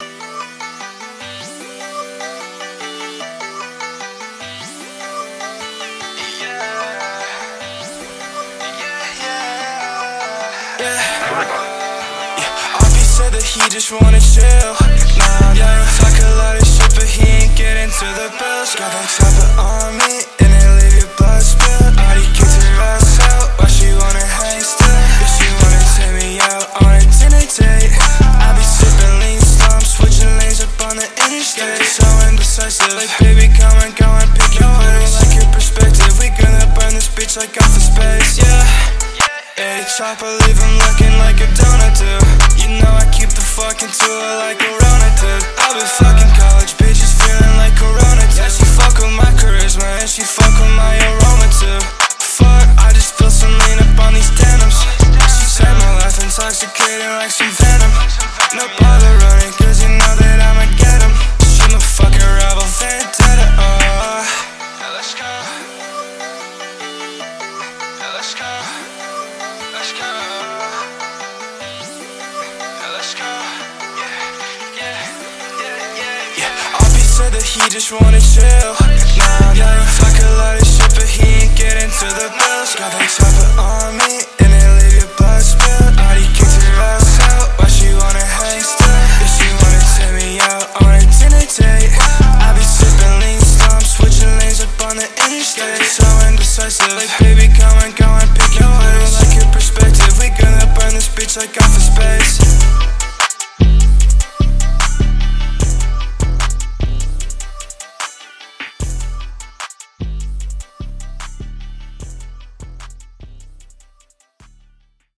BPM150
Audio QualityLine Out